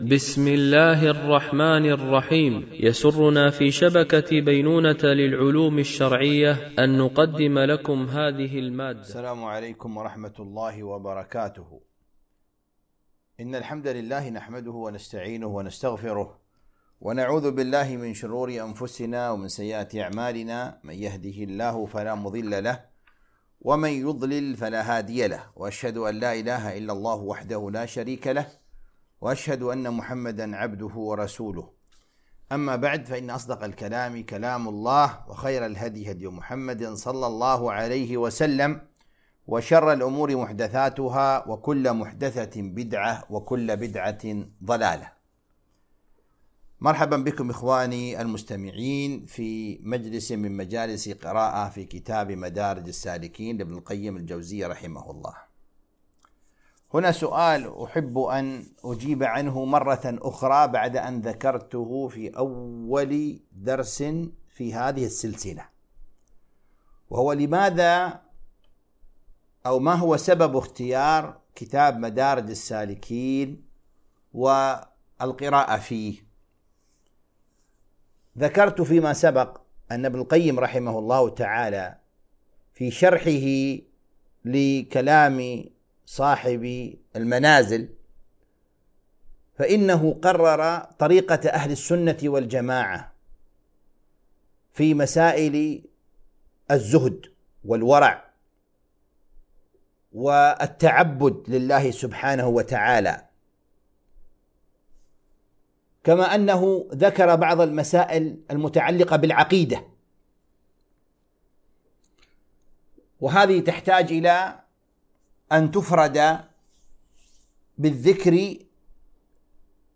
قراءة من كتاب مدارج السالكين - الدرس 64